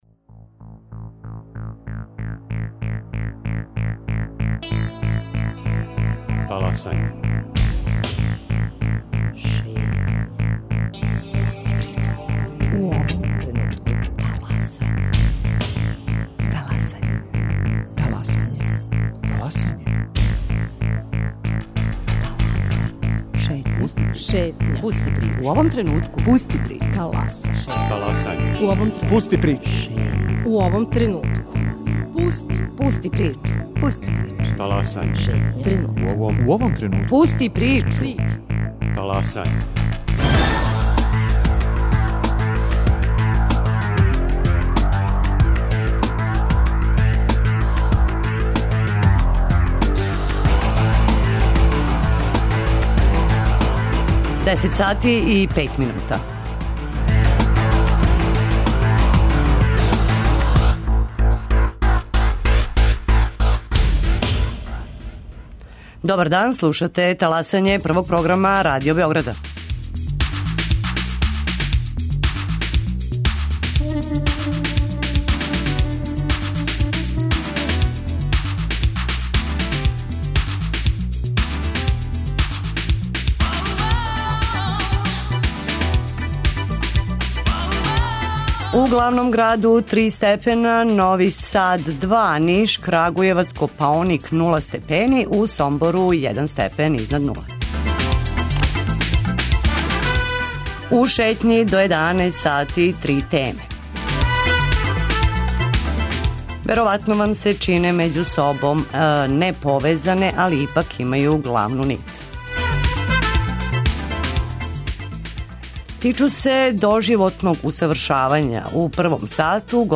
О досадашњим резултатима и даљим плановима у Шетњи говоре представници центра.